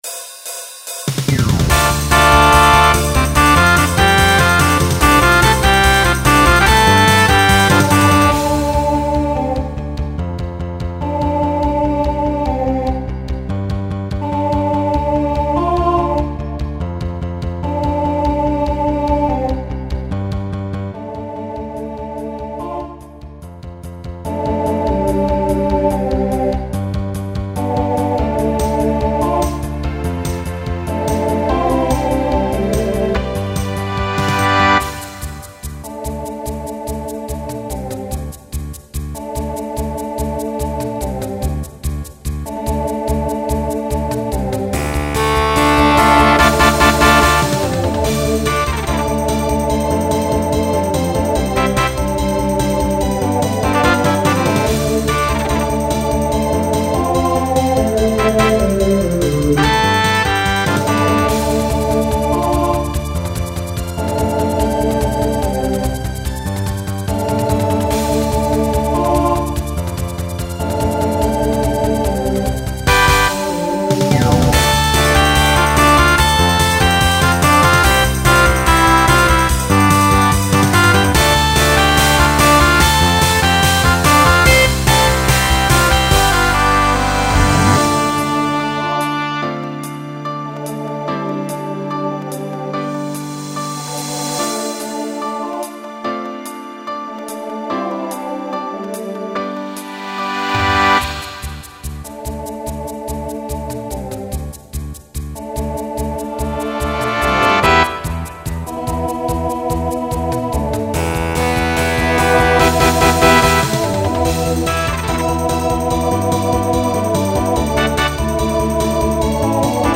Genre Rock Instrumental combo
Transition Voicing TTB